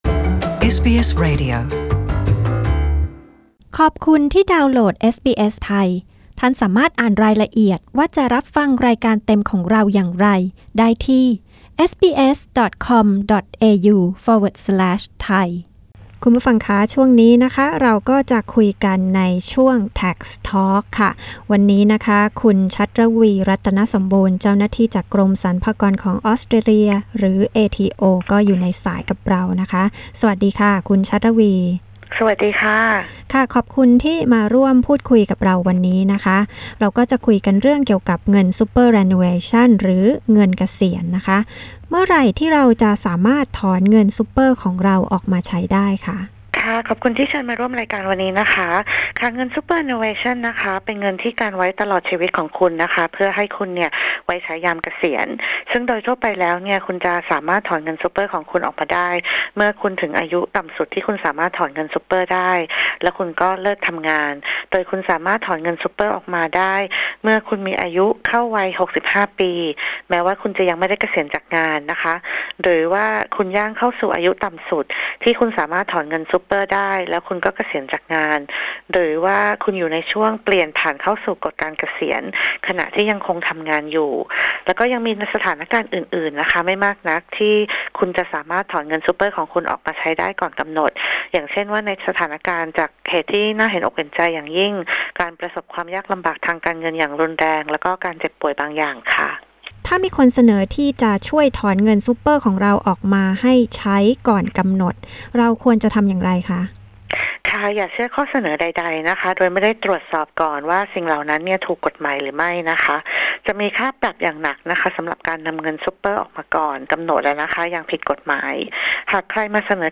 เจ้าหน้าที่กรมสรรพากรออสเตรเลีย อธิบายเงื่อนไขการถอนเงินซูเปอร์อย่างถูกกฎหมาย และการรับมือหากมีใครเสนอที่จะช่วยคุณนำเงินเหล่านั้นออกมาใช้ได้ก่อนเกษียณ